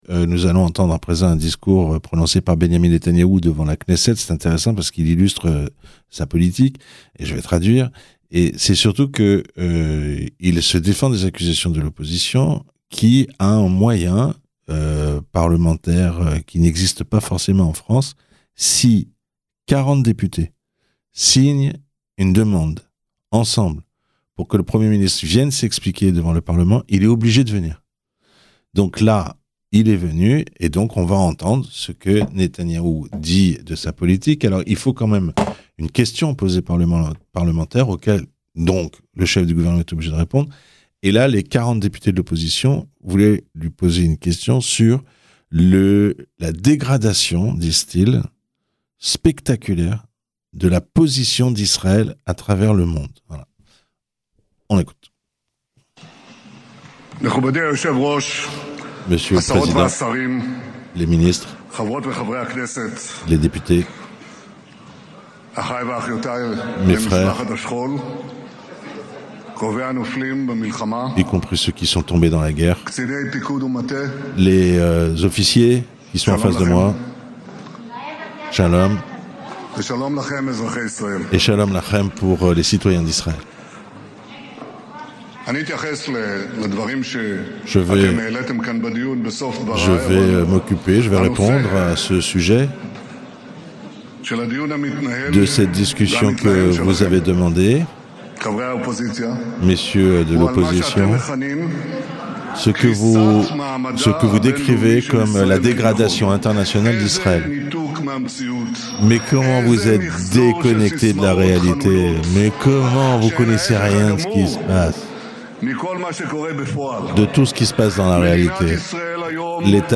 Ecoutez le discours de Netanyahou devant la Knesset traduit en français